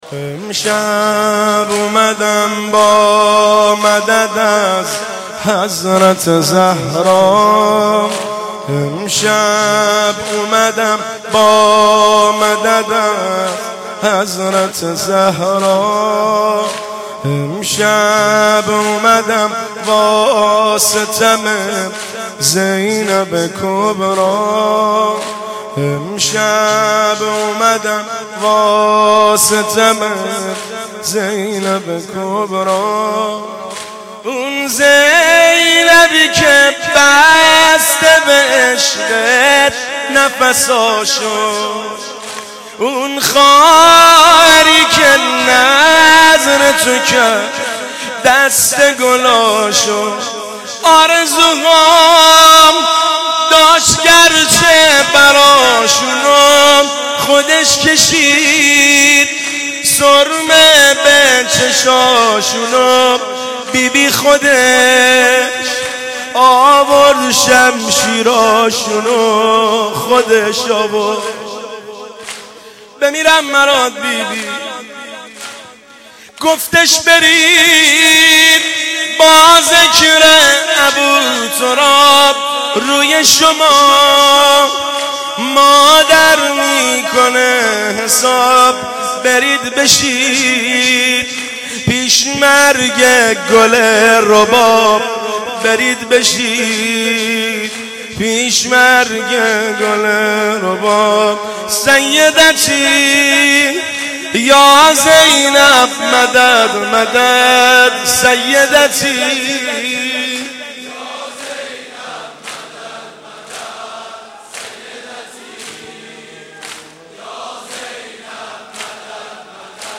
شب چهارم محرم95/هیئت غریب مدینه امیر کلا(بابل)